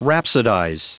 Prononciation du mot rhapsodize en anglais (fichier audio)
Prononciation du mot : rhapsodize
rhapsodize.wav